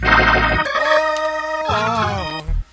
helping me riff Meme Sound Effect
Category: Reactions Soundboard